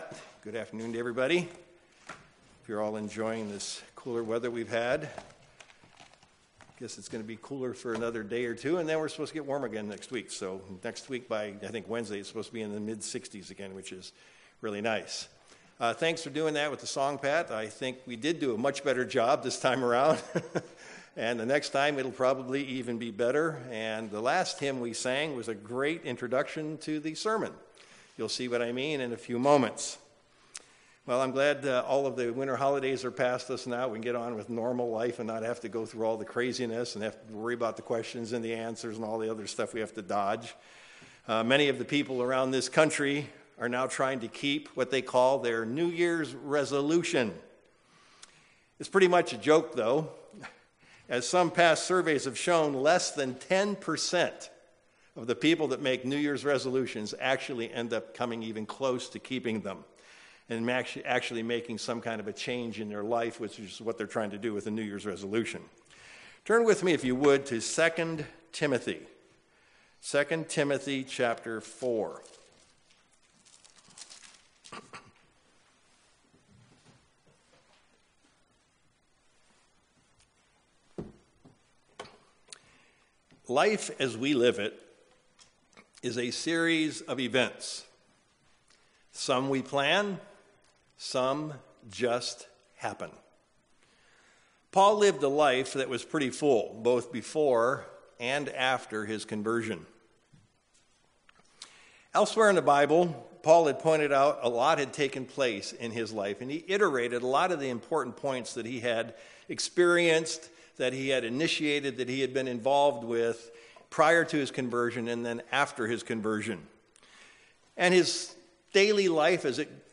Given in Sacramento, CA
UCG Sermon Studying the bible?